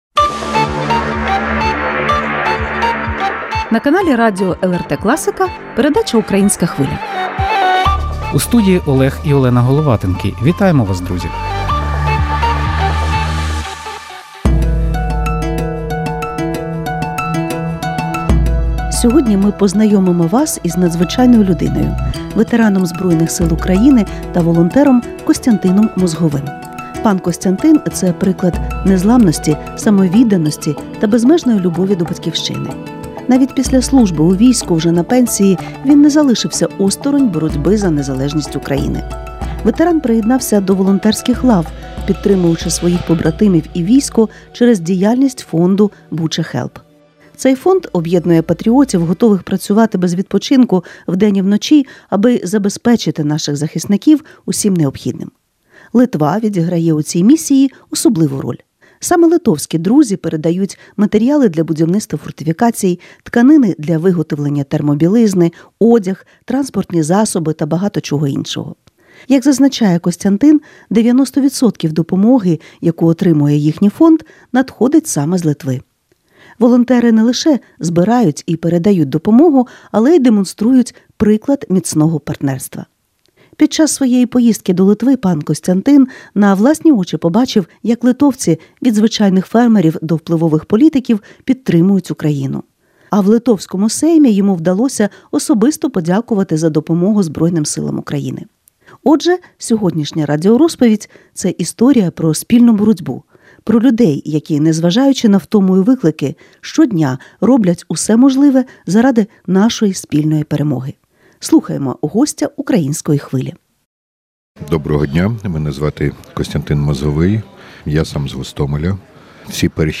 Про все це у розмові з гостем передачі “Українська Хвиля”